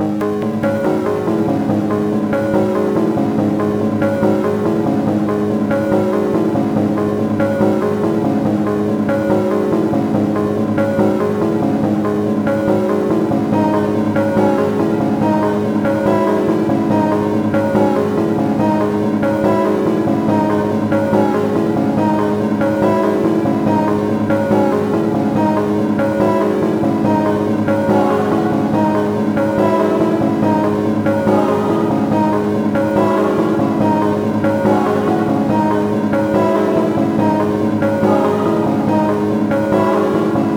SOUTHSIDE_melody_loop_hands_142_C#m.wav